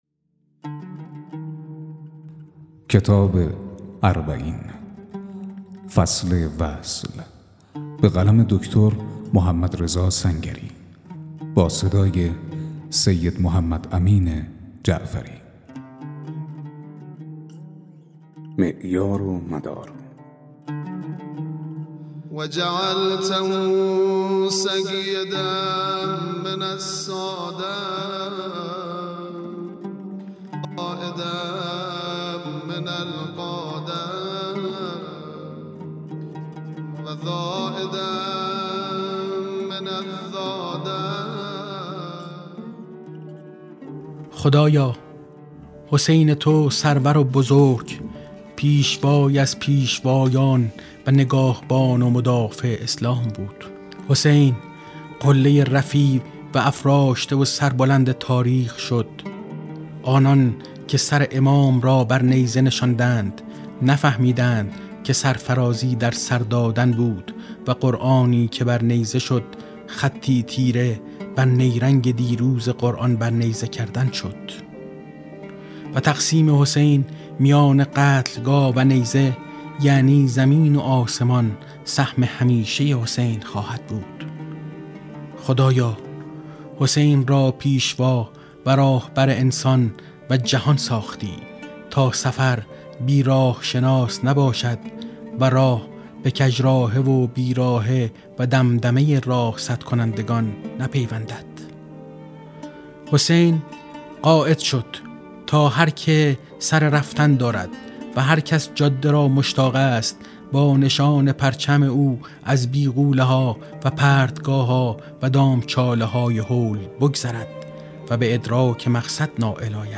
🔻ضبط و آماده‌سازی: استودیو همراز